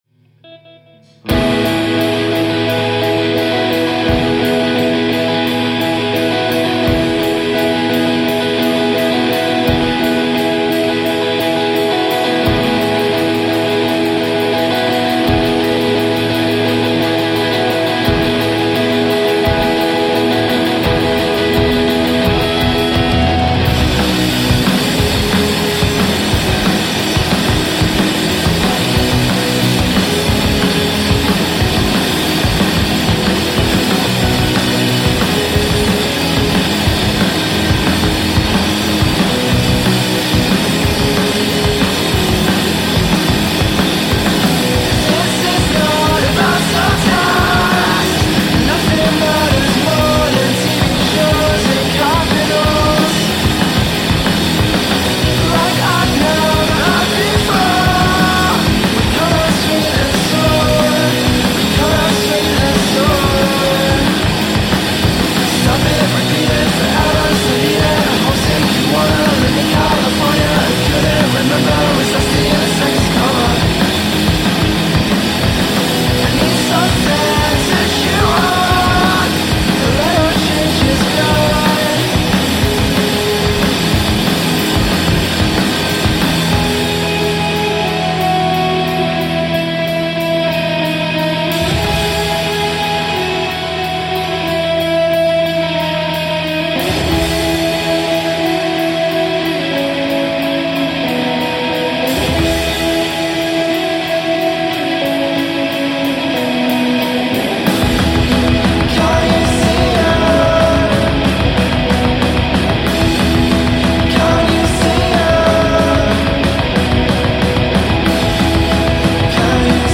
batteria e voce
alla chitarra, definiscono il proprio suono noise-pop